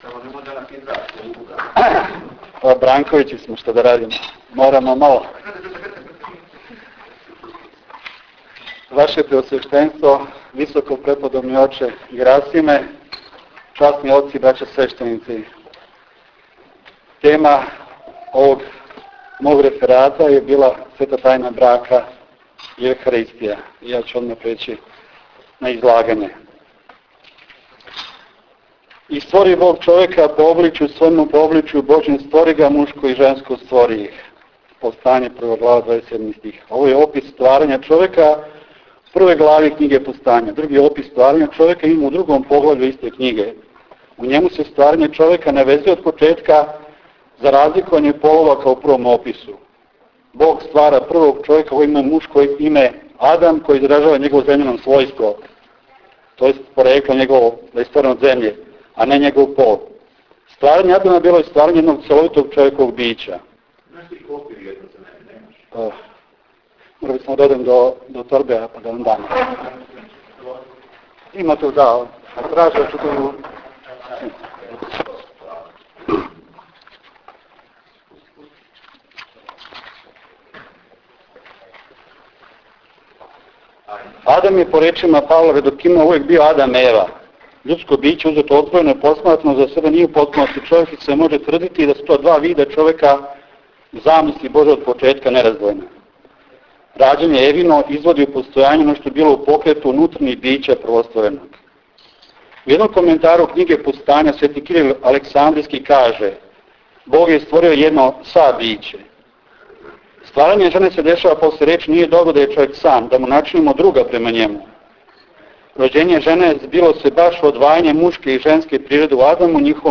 ReferatKragujevac.wav